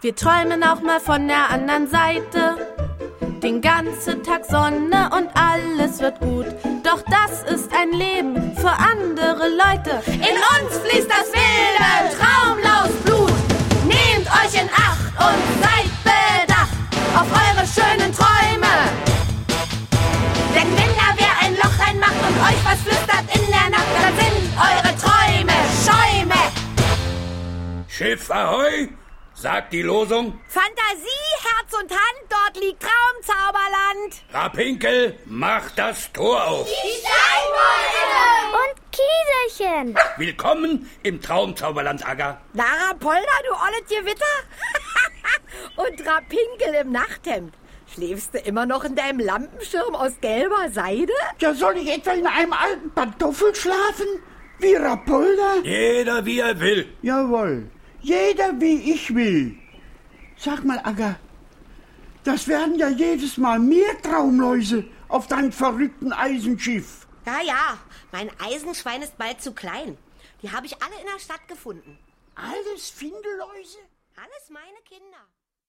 Ravensburger Der Traumzauberbaum 2 - Agga Knack, die wilde Traumlaus ✔ tiptoi® Hörbuch ab 3 Jahren ✔ Jetzt online herunterladen!